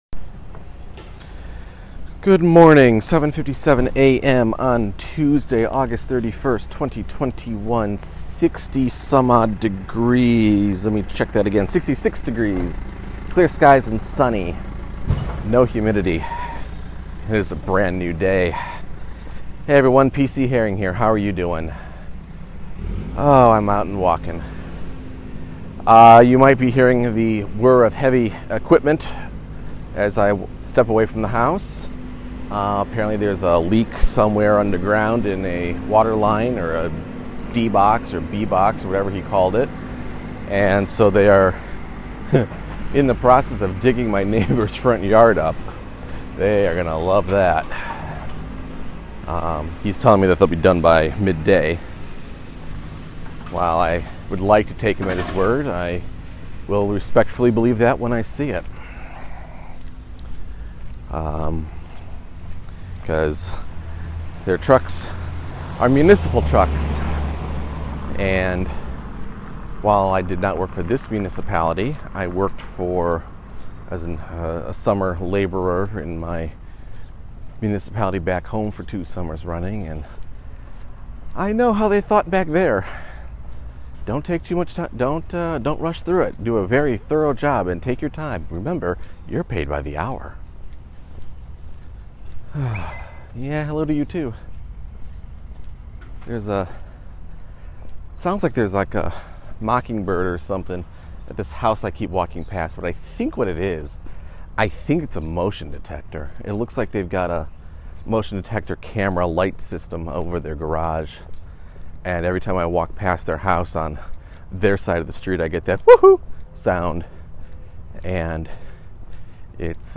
It’s a bit of a somber walk today as I reflect on how life has changed over the past year and a half and how the things I chose to do before the pandemic, and before Baby Girl was born, are not necessarily things I can continue to do today.
Sorry it’s not more upbeat. http